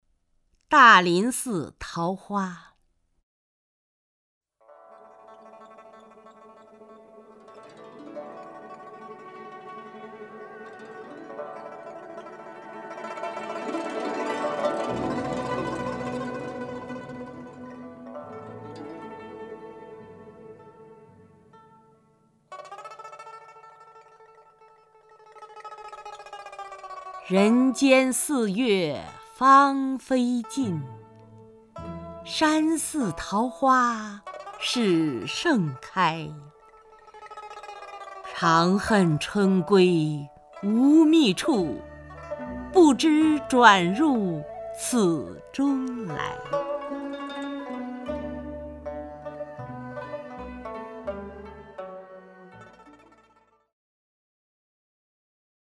曹雷朗诵：《大林寺桃花》(（唐）白居易) （唐）白居易 名家朗诵欣赏曹雷 语文PLUS